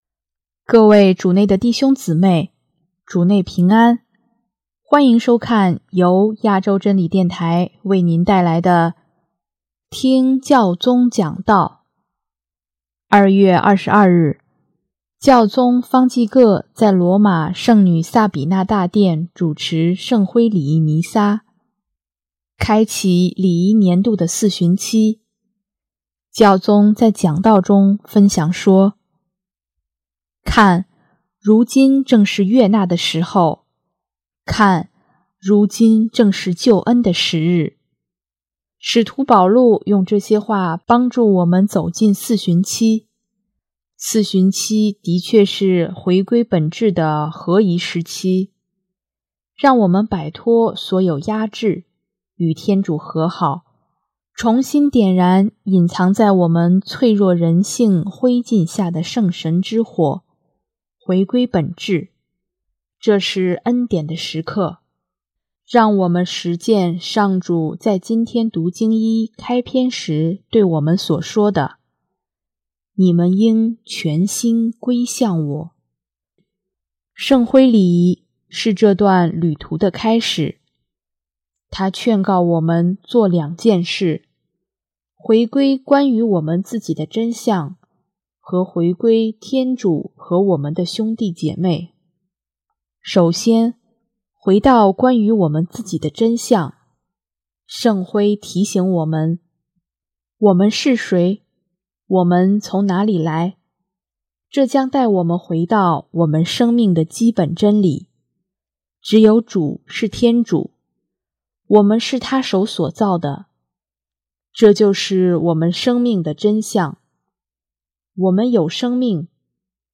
2月22日，教宗方济各在罗马圣女萨比娜大殿主持圣灰礼仪弥撒，开启礼仪年度的四旬期。